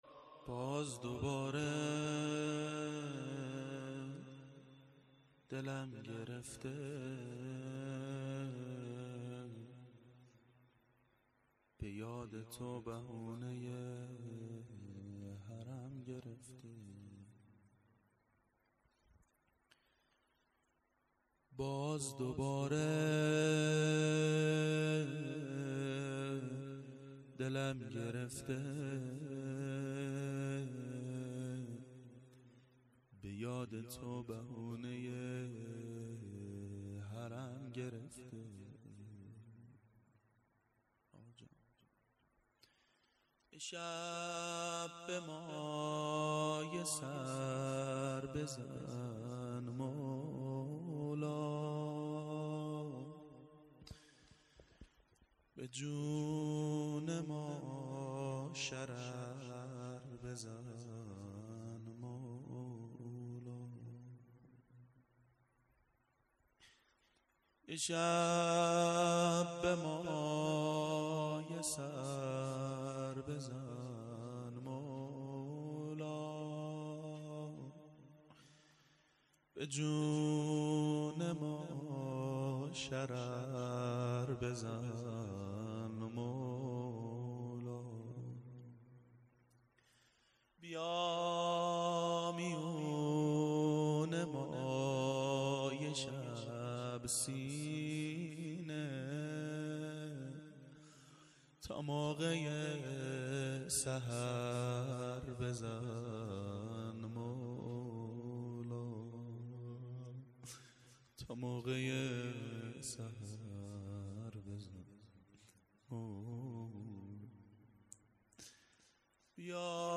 باز دوباره دلم گرفته.مناجات باامام زمان علیه السلام
ظهر شهادت حضرت زهرا سلام الله علیها1392 هیئت شیفتگان حضرت رقیه س
01-باز-دوباره-دلم-گرفته-و-مناجات.mp3